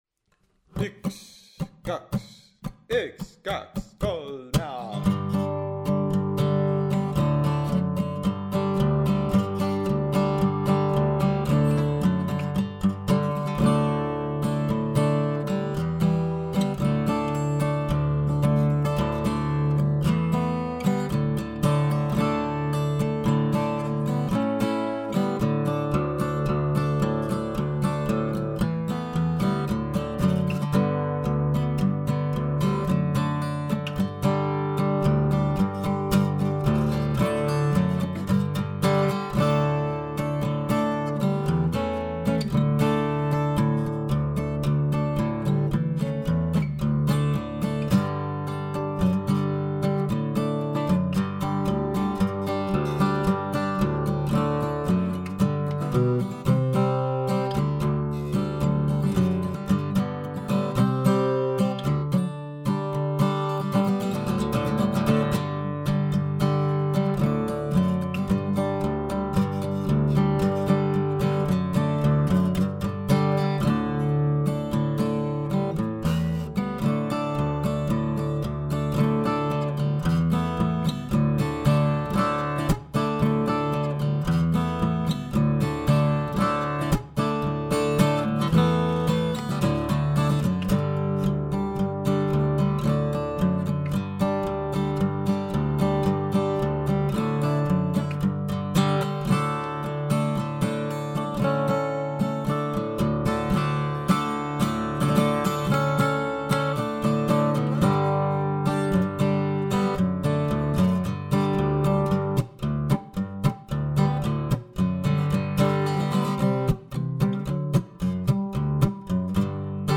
Kunda polka